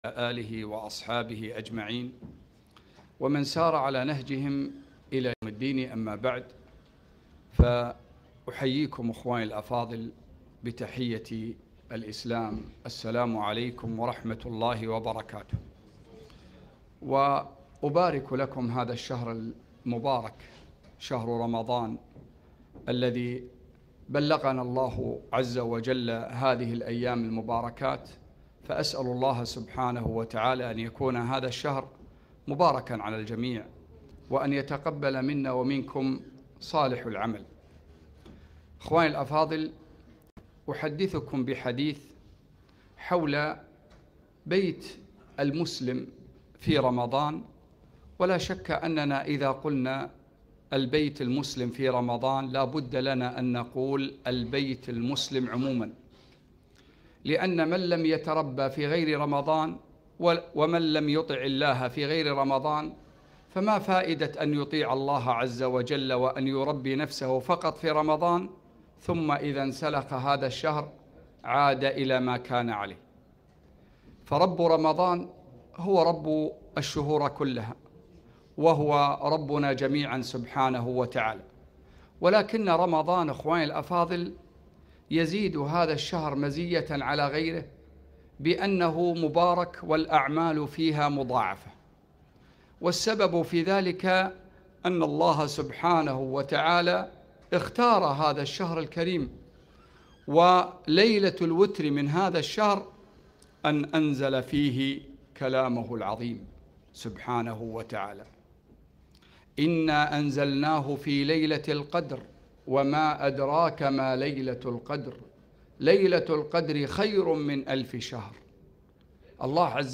محاضرة - البيت المسلم في رمضان